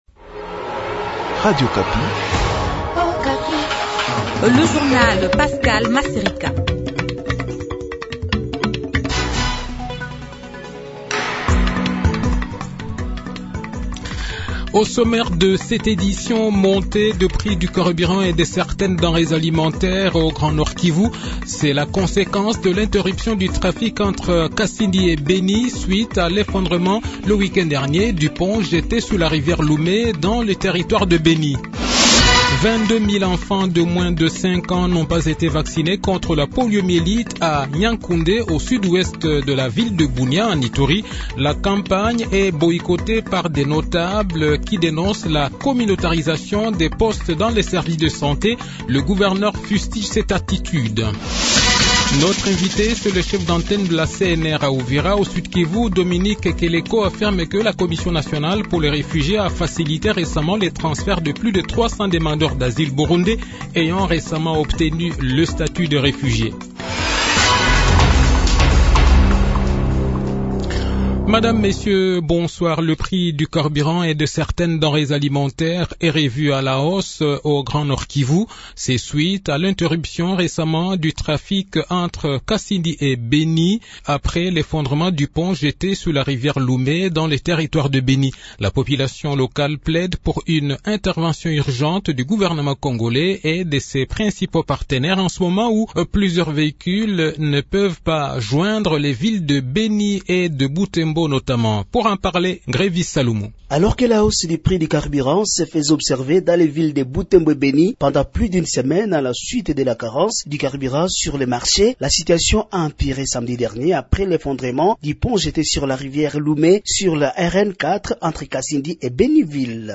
Le journal de 18 h, 12 decembre 2022